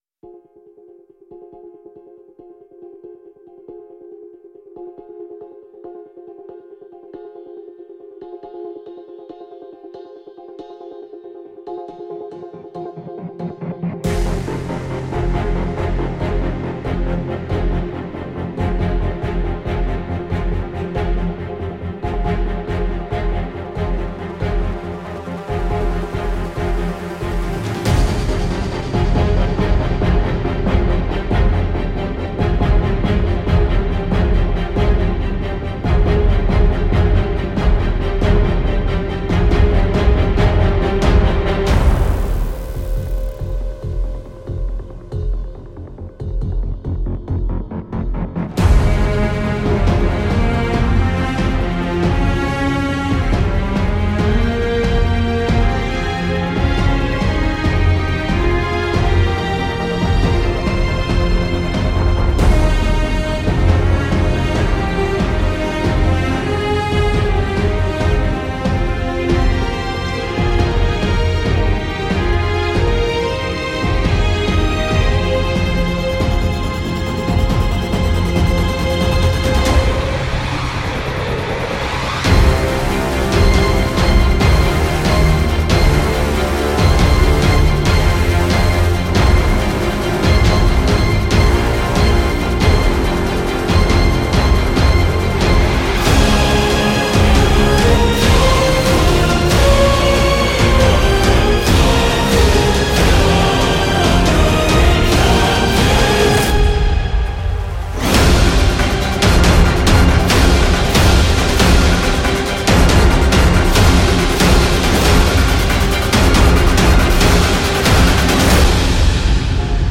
以下试听除弦乐外的其他乐器和打击乐均来自柏林系列，本站均可下载